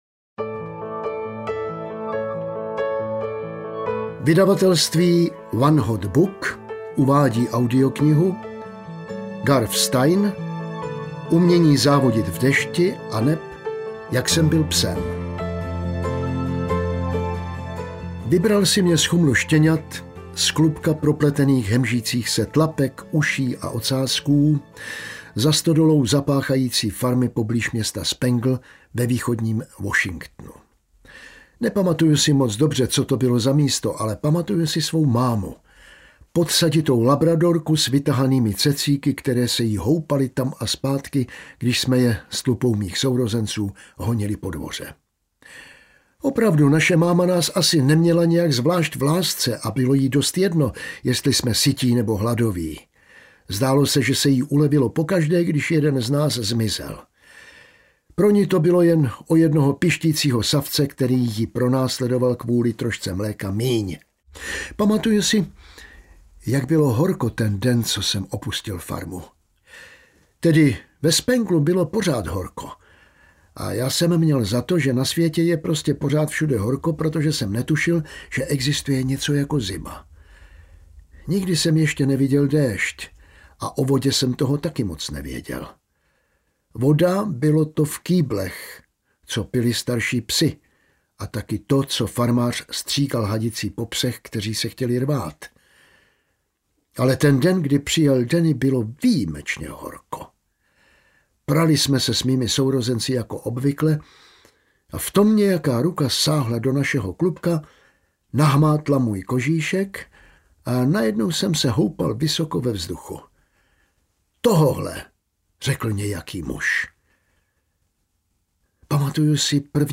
Umění závodit v dešti audiokniha
Ukázka z knihy